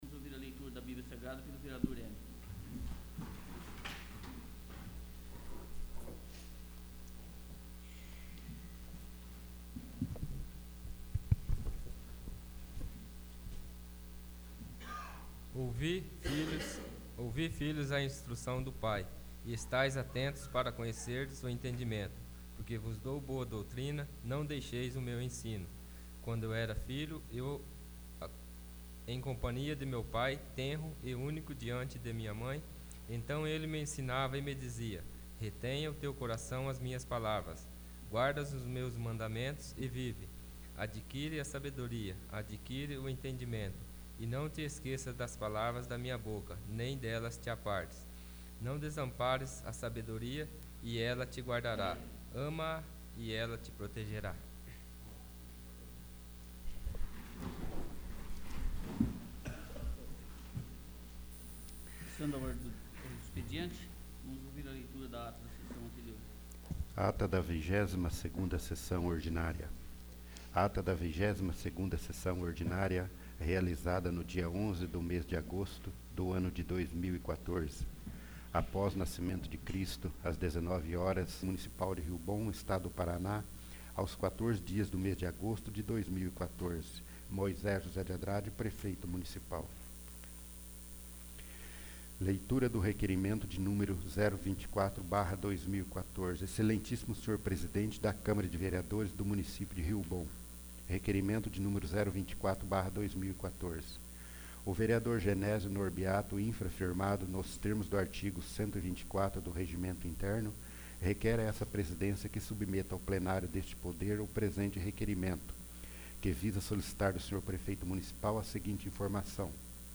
23º. Sessão Ordinária